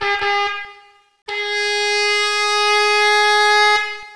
air_horns_3.wav